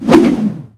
tentakle.ogg